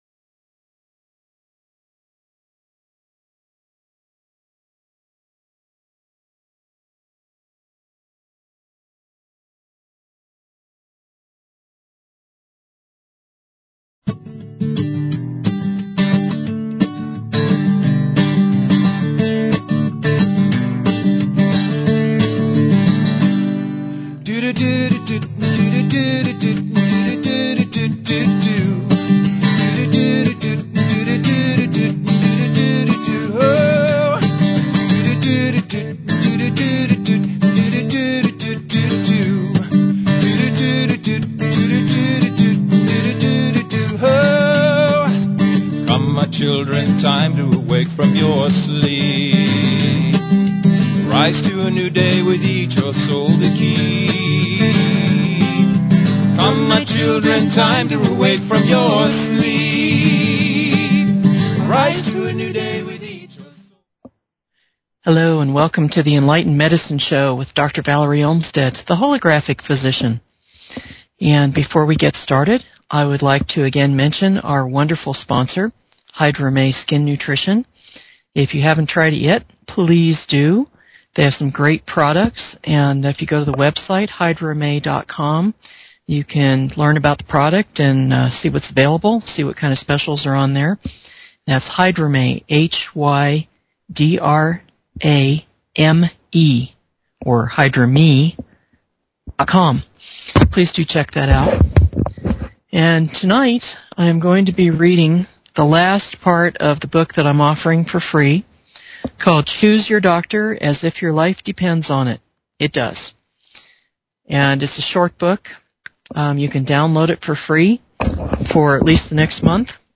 Talk Show Episode, Audio Podcast, Enlightened_Medicine and Courtesy of BBS Radio on , show guests , about , categorized as
continued reading from the eBook: Choose Your Doctor As if Your Life Depends On It (it does).